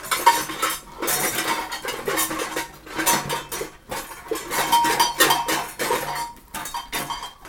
Index of /90_sSampleCDs/Roland L-CD701/PRC_FX Perc 1/PRC_Long Perc